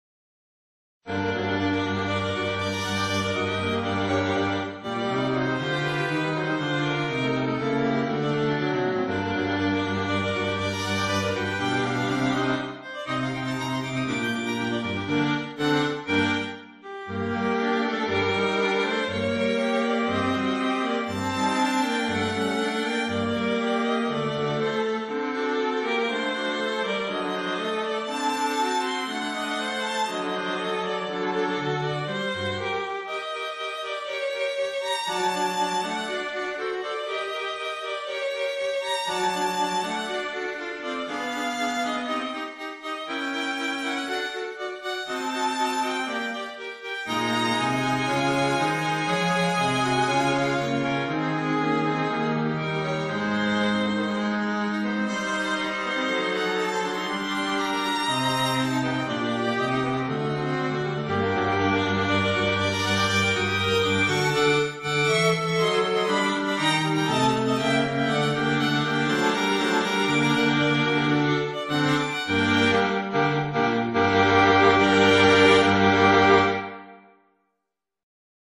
En juillet 1844 il recevait un deuxième prix d'harmonie vocale et instrumentale partagé avec Mangeant et l'année suivante décrochait le premier prix décerné à l’unanimité, avec deux épreuves : une basse donnée pour être chiffrée et réalisée à quatre parties vocales (style sévère et fuguée) et un chant donné pour être écrit à quatre parties instrumentales (composition dans le genre libre).
Devoir d'harmonie instrumentale de Crévecoeur lors de l’obtention de son 1er prix d'harmonie en juillet 1845, in la réédition en 1858 du Traité de Colet.